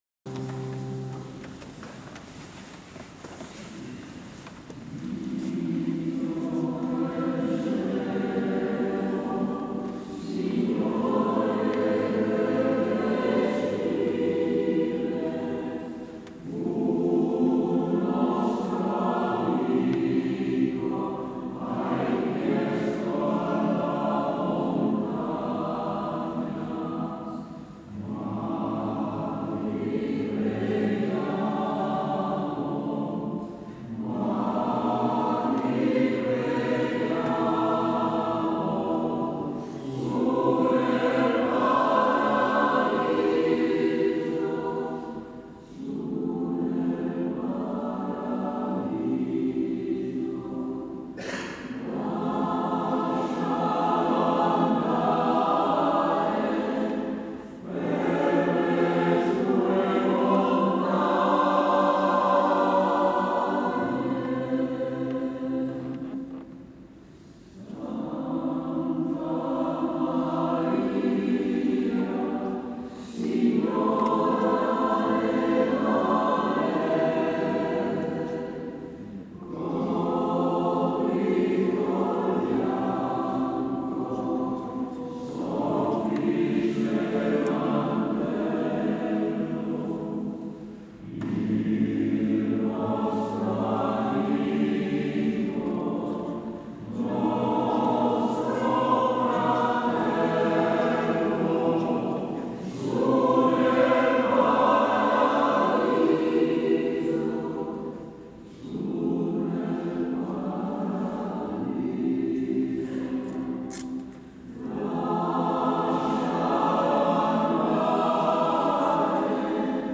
Messa
Il Coro ha cantato "